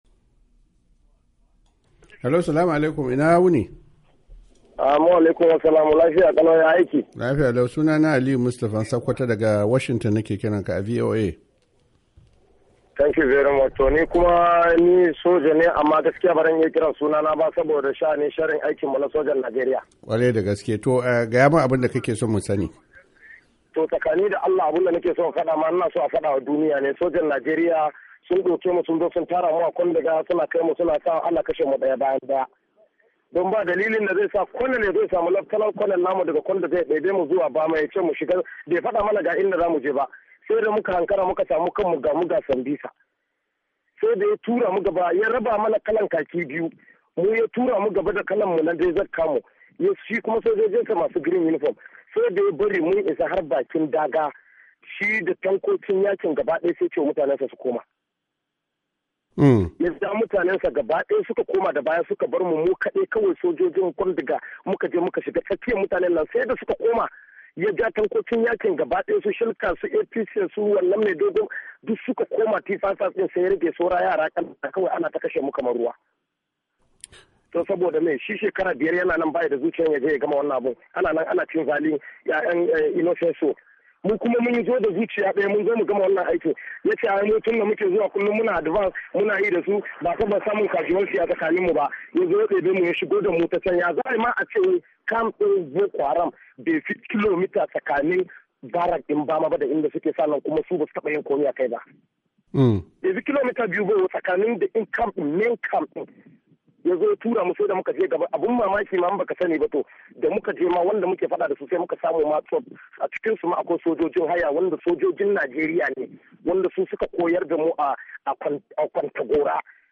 Wani mutum da yace shi sojan Najariya ne daga Kwanduga a jihar Borno